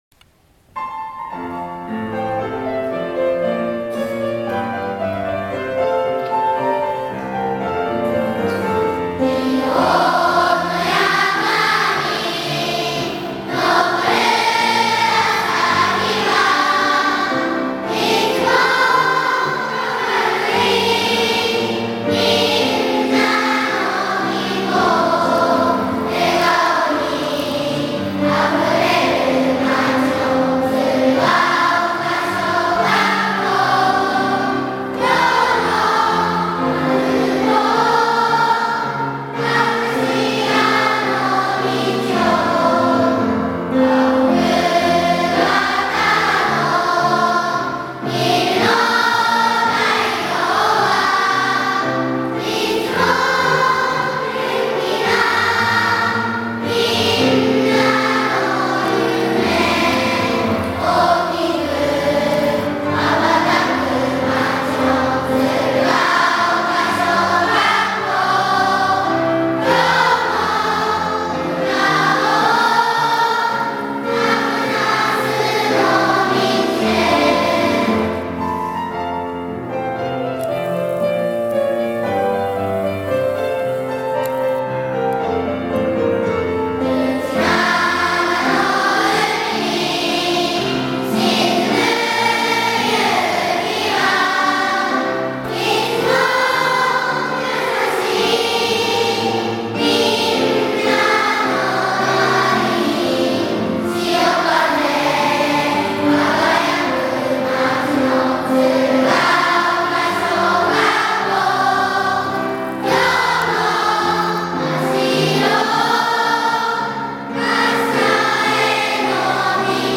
（平成８年４月１日制定） 鶴ケ丘小学校校歌（ピアノ伴奏）.wma 鶴ケ丘小学校歌（児童の声）.mp3 ※クリックすると再生されます。